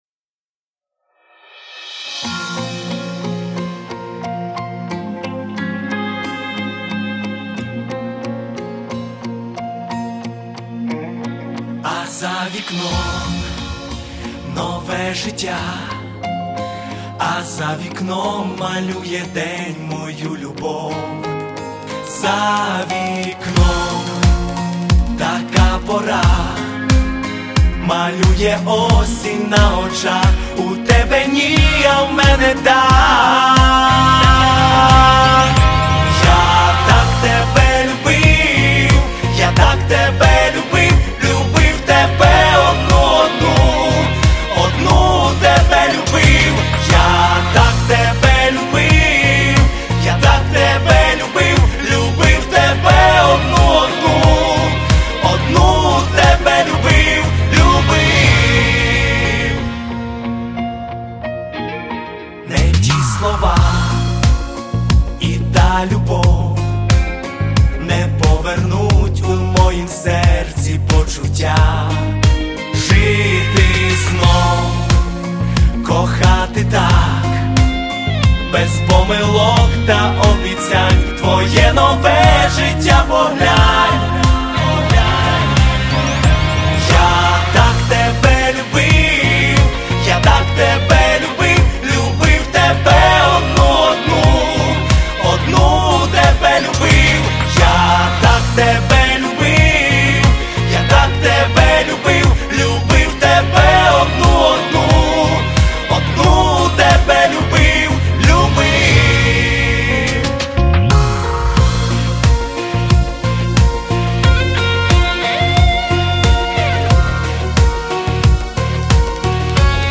Всі мінусовки жанру Pop
Плюсовий запис